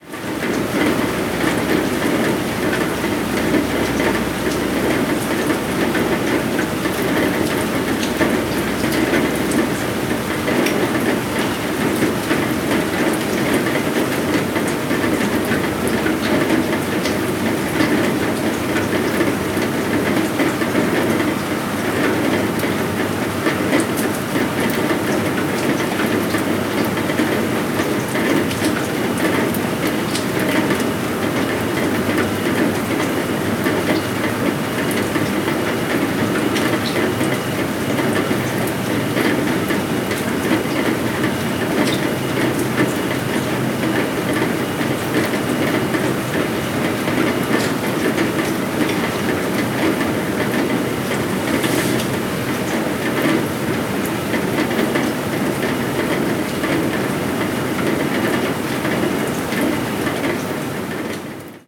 Lluvia cayendo sobre un tejado